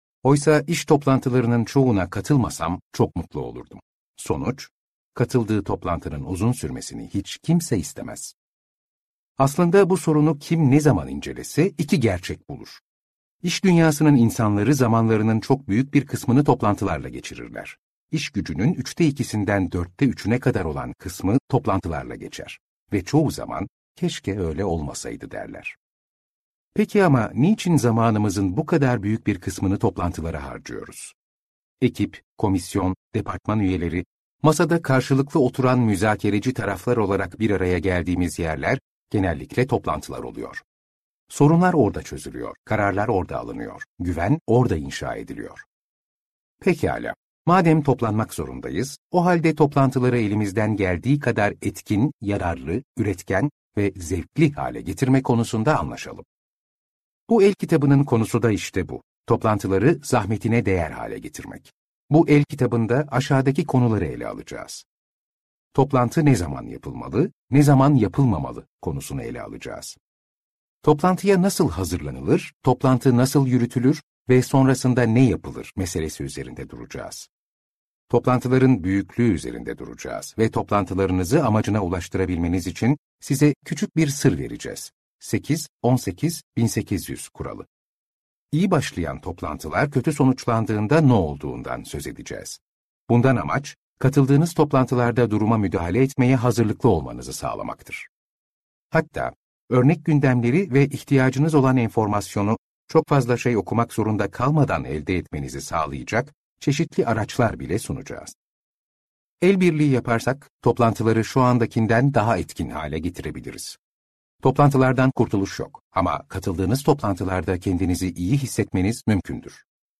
Toplantı Yönetimi - Seslenen Kitap